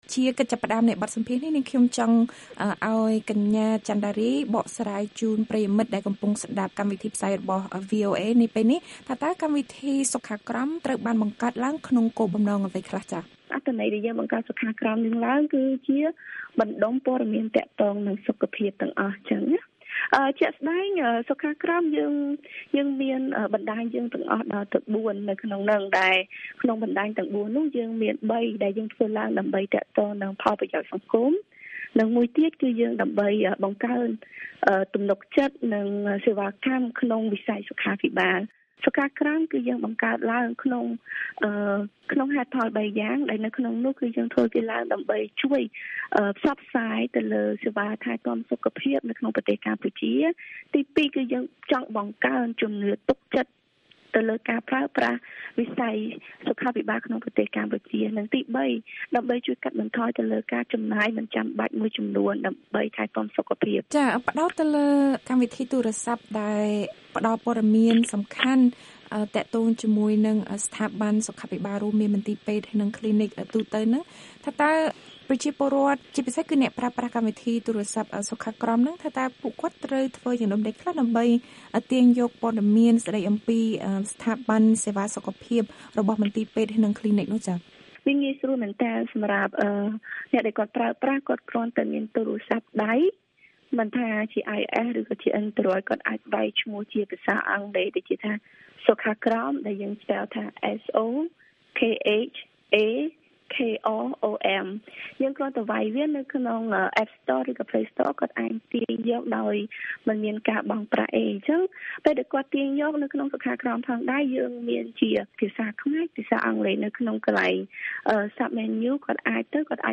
បទសម្ភាសន៍ VOA៖ កម្មវិធីទូរស័ព្ទសុខក្រមលើកកម្ពស់ការយល់ដឹងពីសុខាភិបាលដល់សាធារណៈ